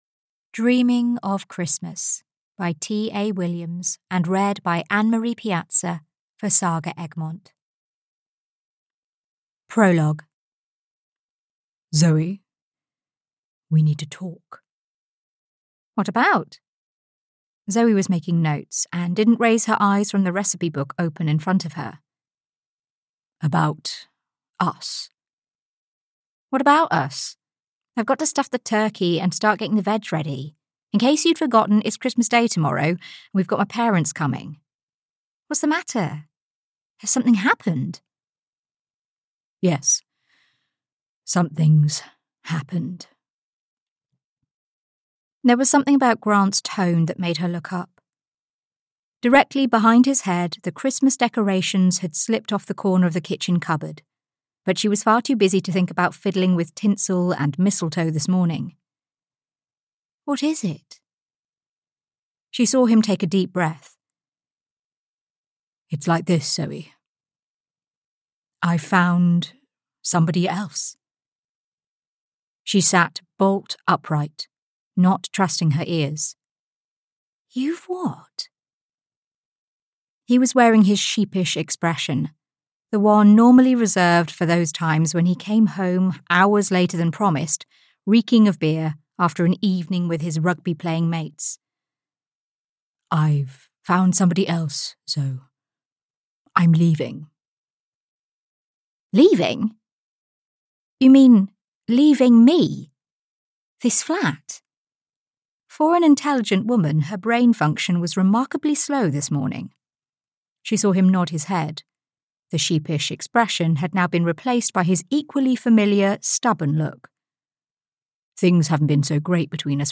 Dreaming of Christmas (EN) audiokniha
Ukázka z knihy